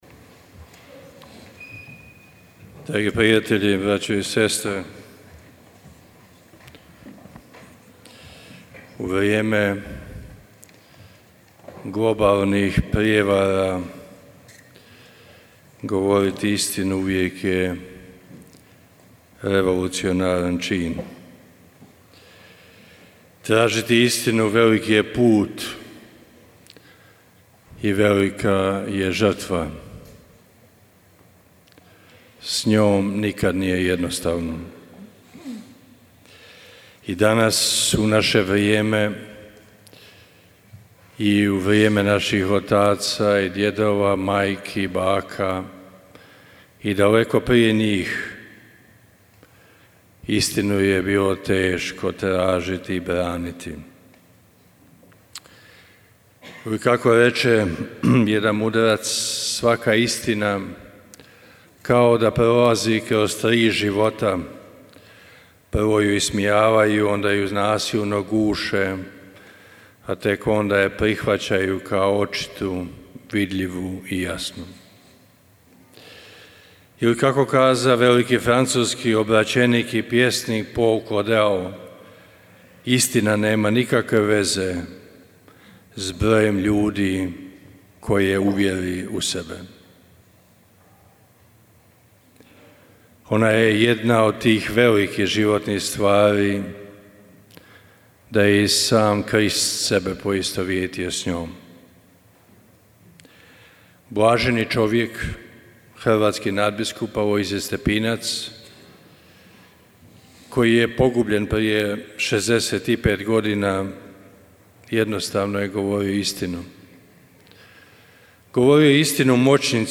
na Stepinčevo u Međugorju: Želimo li biti sveti, govorimo istinu. Uvijek i svugdje